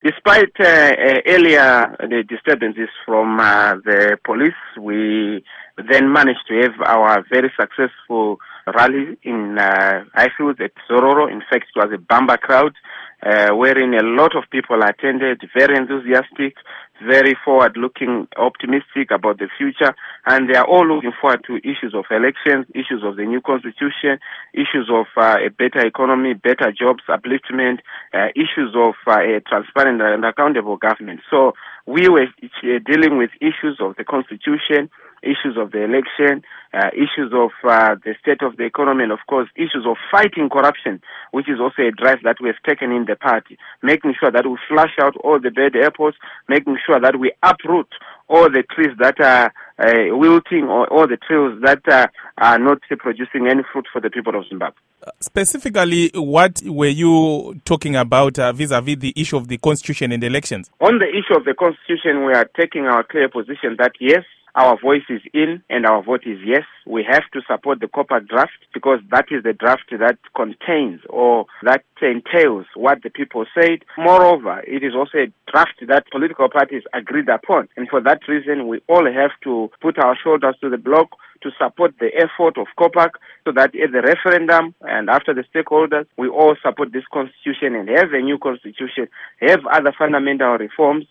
Interview With Nelson Chamisa